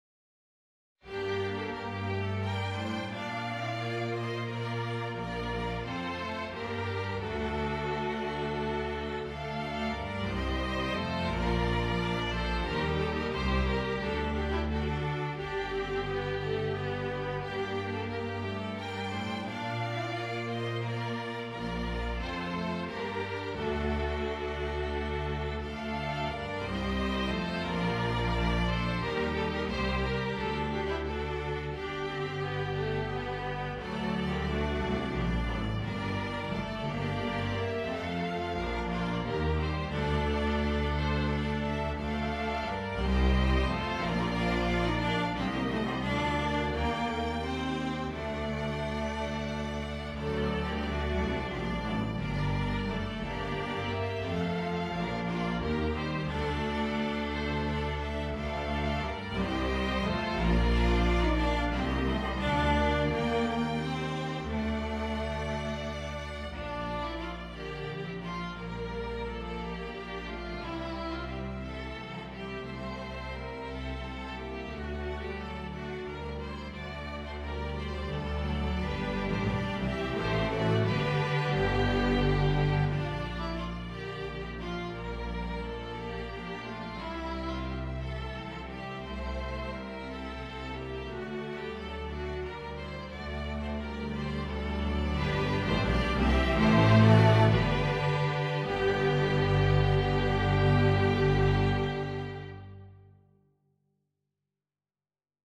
Thus it readily suits a string quintet.